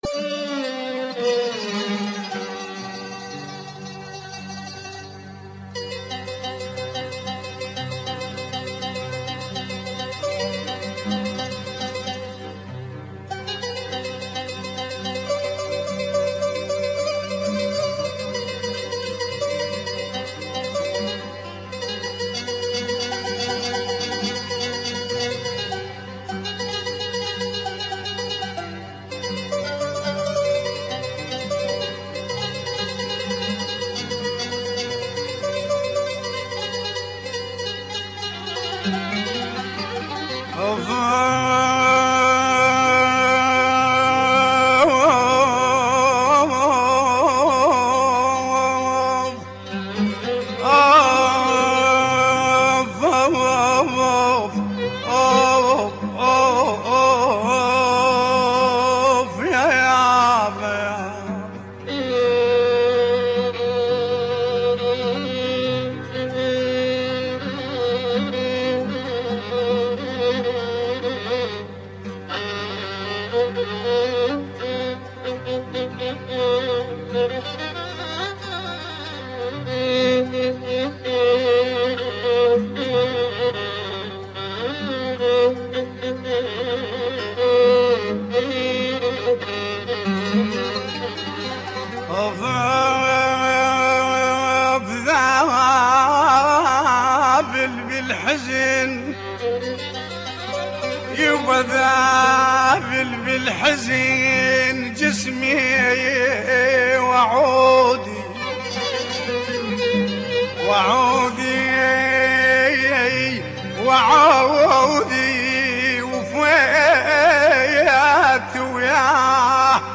اغاني عراقيه